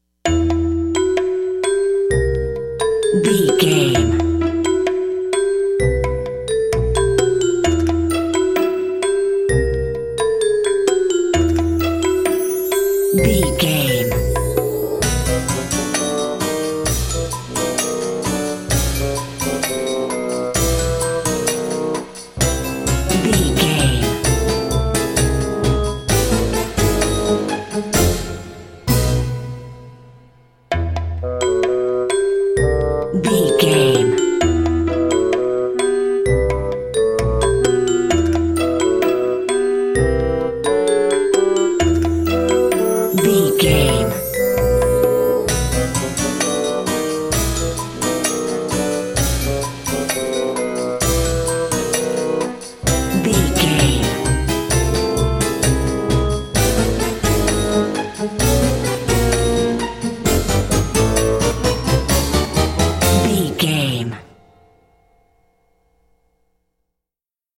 Aeolian/Minor
orchestra
percussion
silly
circus
goofy
comical
cheerful
perky
Light hearted
quirky